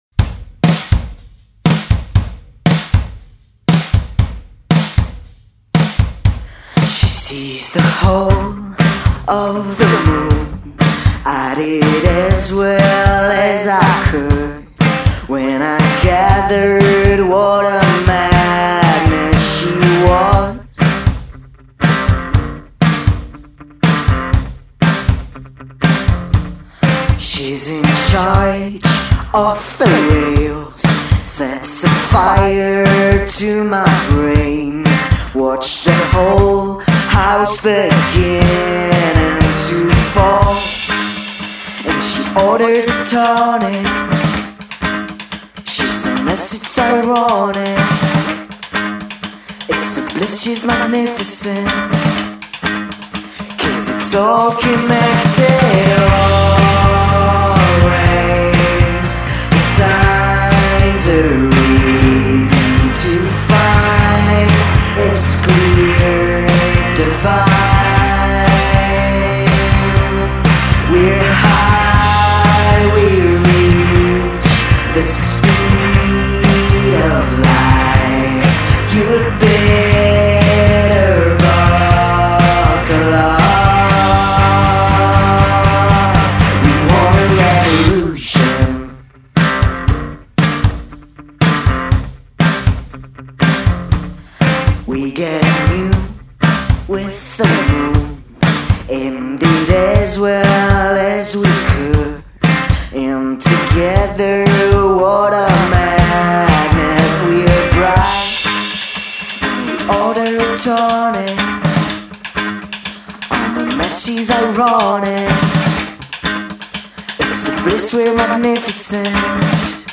Interview de l'artiste (32:57)